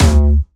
Jumpstyle Kick 1